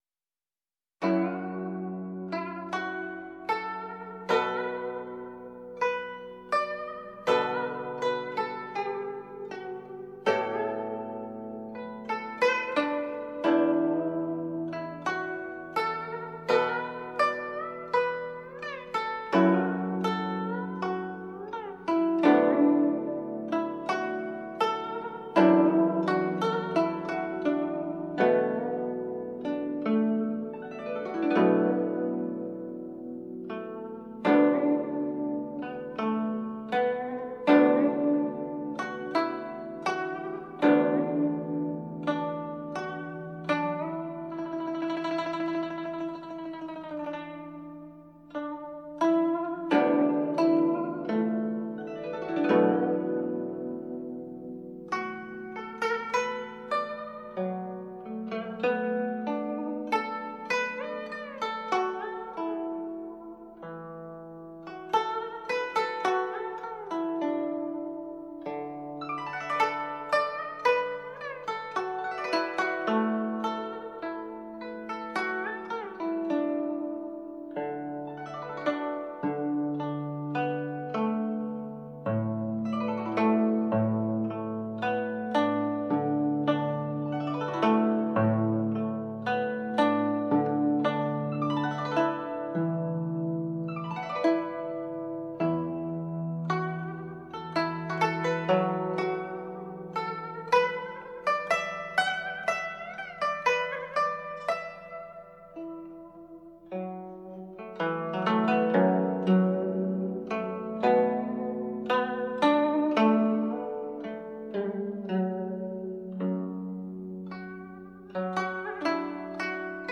精彩展示六十多种乐器音色，全面感受吹拉弹打缤纷世界！
弹拨乐器2
古筝